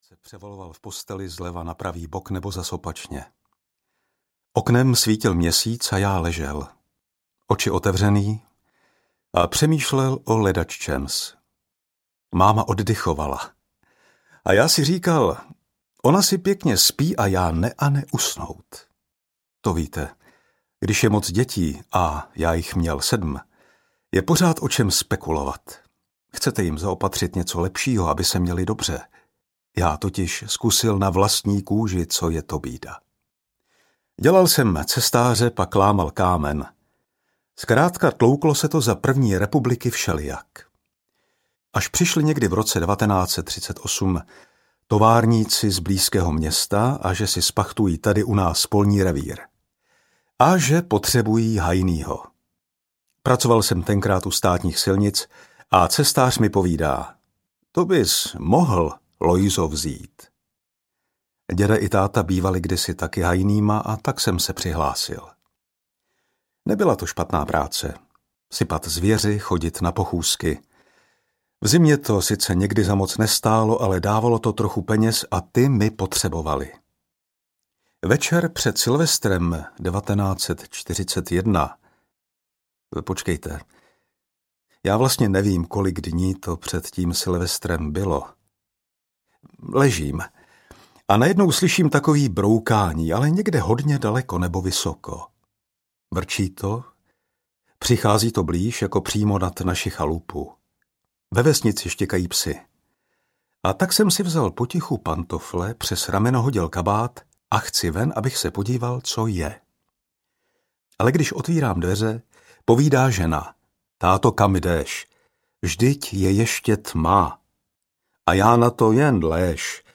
Ukázka z knihy
Interpretem audioknihy je herec František Kreuzmann.
atentat-na-reinharda-heydricha-audiokniha